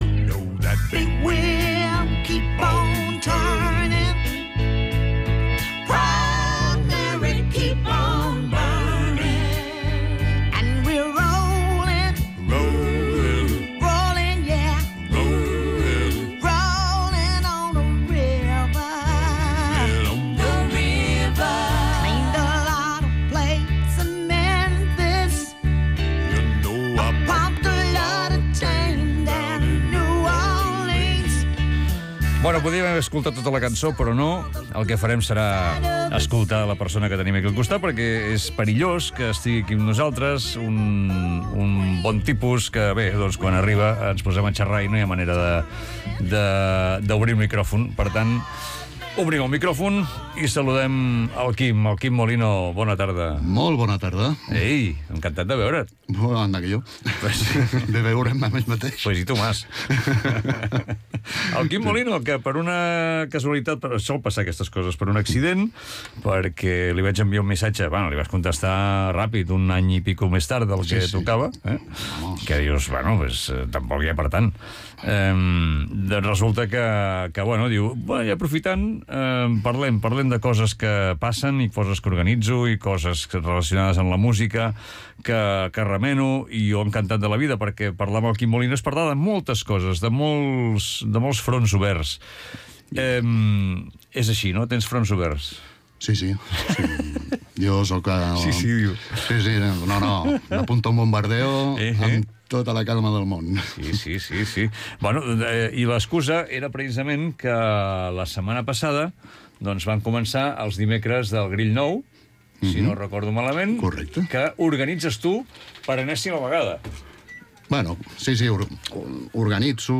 Radiofórmula